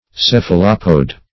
Search Result for " cephalopode" : The Collaborative International Dictionary of English v.0.48: Cephalopod \Ceph"a*lo*pod\ (s[e^]f"[.a]*l[-o]*p[o^]d), Cephalopode \Ceph"a*lo*pode\ (s[e^]f"[.a]*l[-o]*p[=o]d), n. (Zool.)
cephalopode.mp3